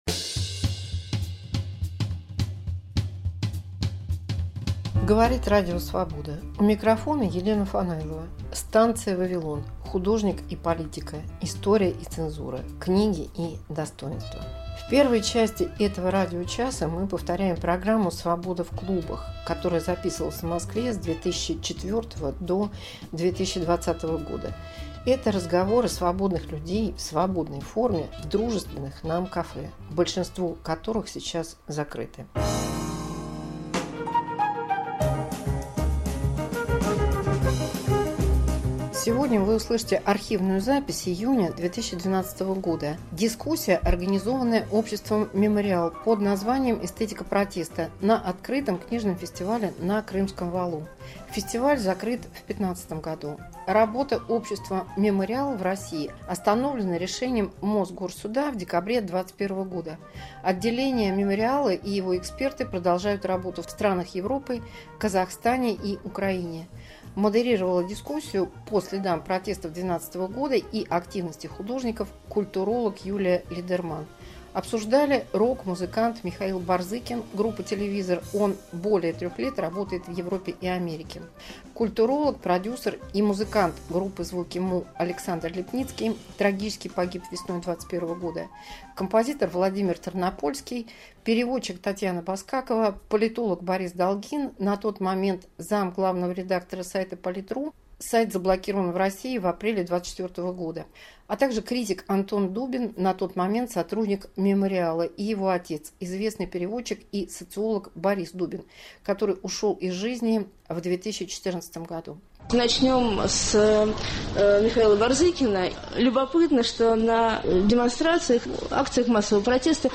Об уличных и художественных формах российского протеста в 2012 году, архивная запись